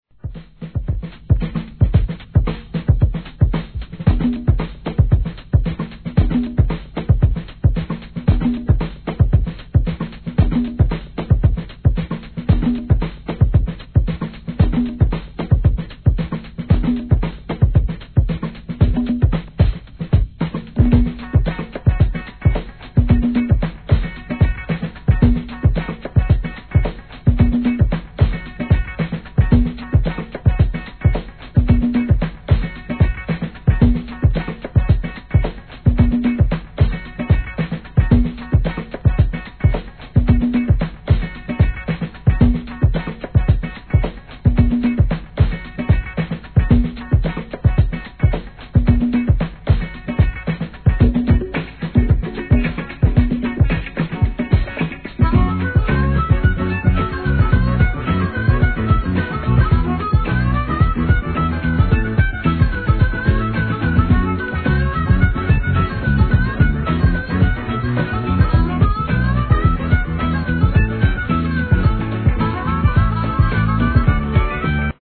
HIP HOP/R&B
そして、「鳴り」にもこだわり音質にまで手を加え、BONUS BEATSなどのディティールにまでこだわった内容。
ブレイク・ビーツ 1.